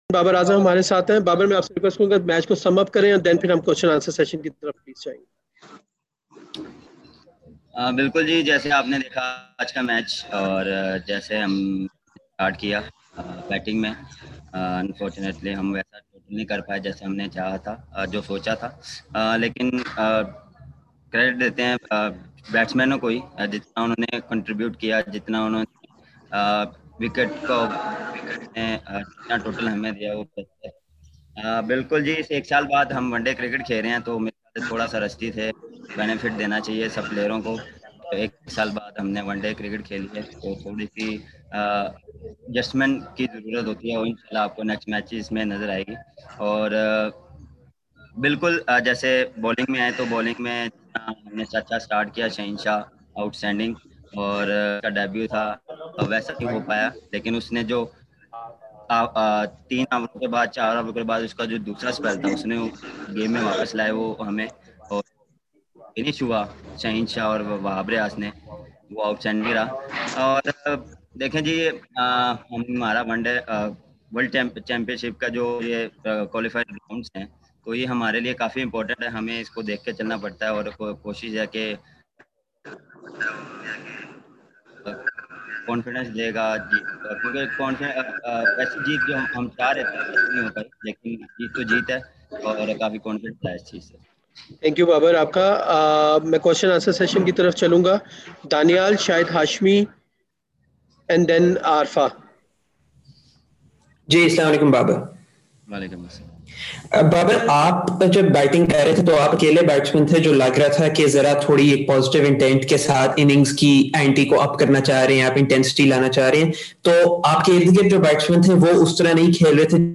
Babar Azam reviews the first Pakistan v Zimbabwe ODI